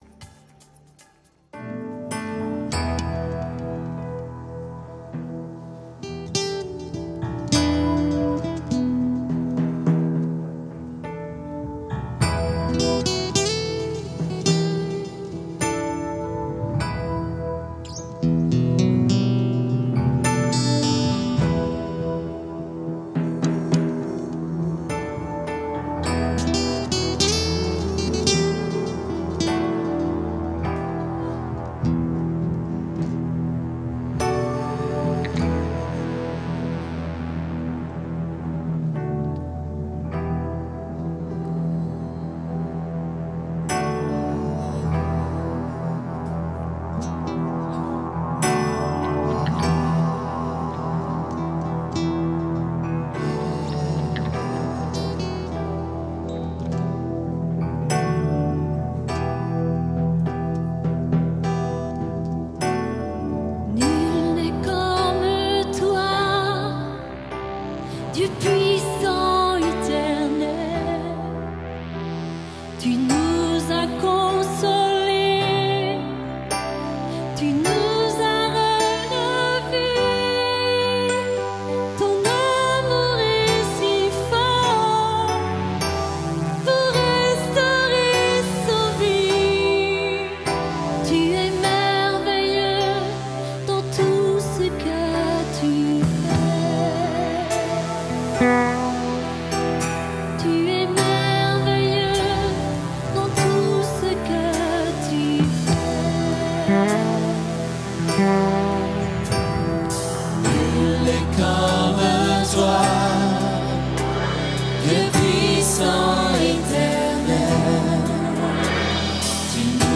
Chants de veillée et de louange